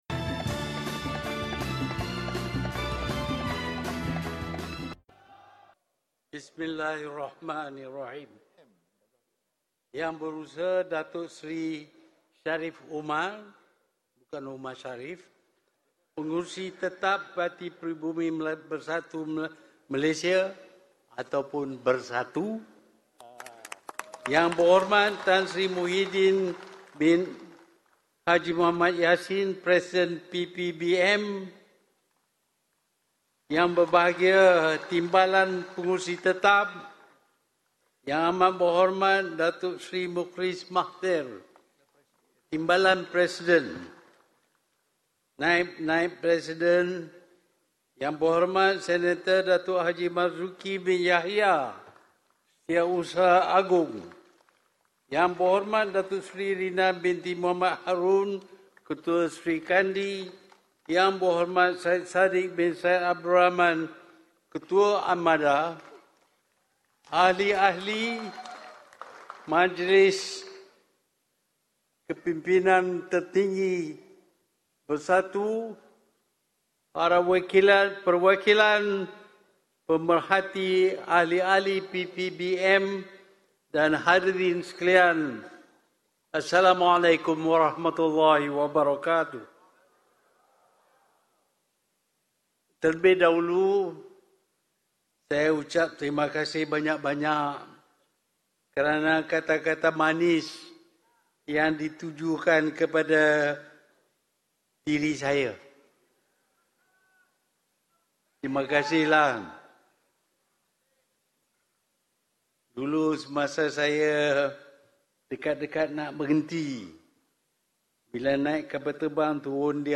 Berikut adalah ucapan penangguhan Pengerusi Bersatu, Tun Dr Mahathir Mohamad di hari terakhir Perhimpunan Agung Tahunan PPBM 2018 yang berlangsung di PICC, Putrajaya.